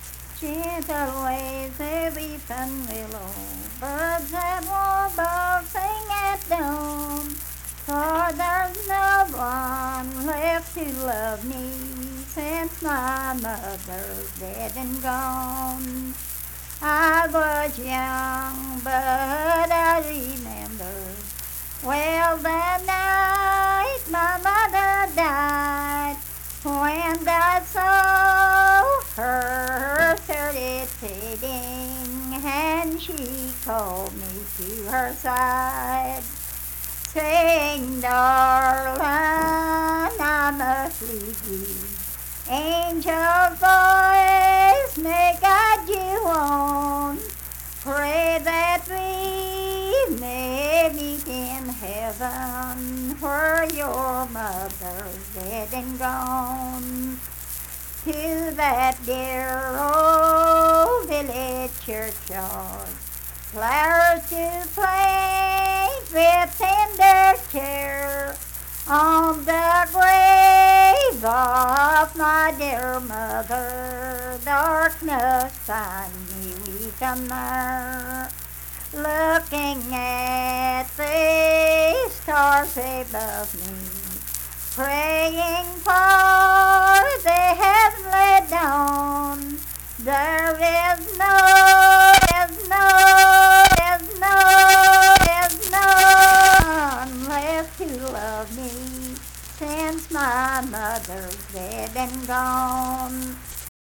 Unaccompanied vocal music performance
Verse-refrain 5(4).
Voice (sung)